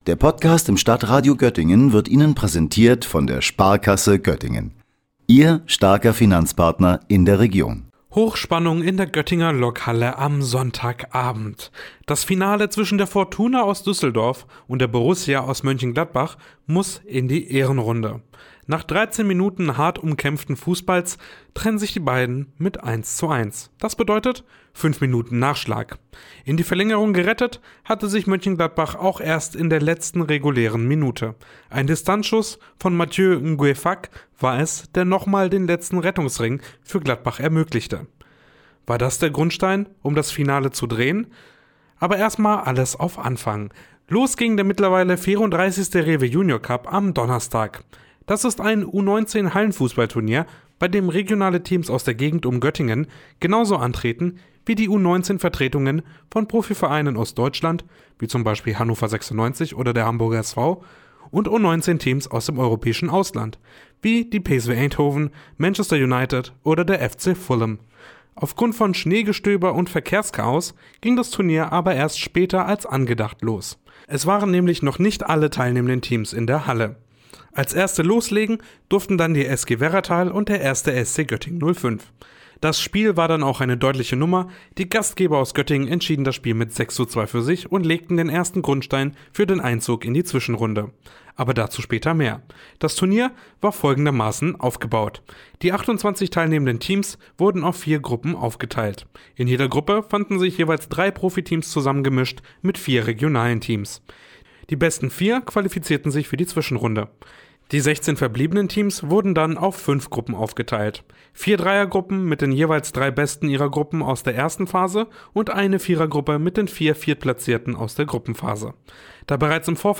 war in der Lokhalle vor Ort und blickt zurück.